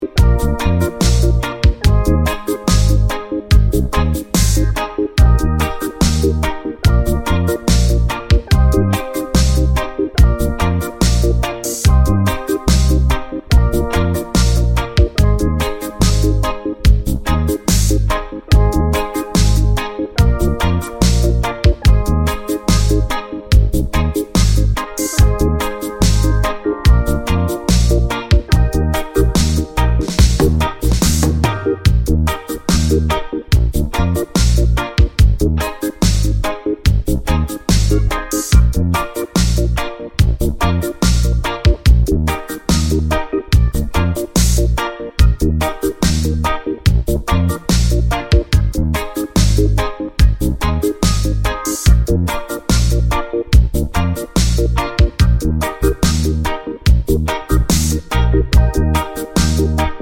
no Backing Vocals Reggae 4:09 Buy £1.50